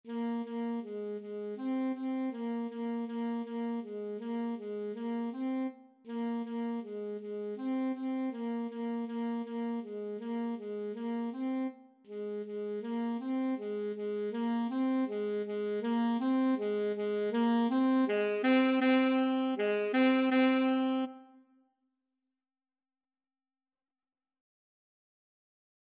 Free Sheet music for Alto Saxophone
2/4 (View more 2/4 Music)
Ab4-C5
Saxophone  (View more Beginners Saxophone Music)
Classical (View more Classical Saxophone Music)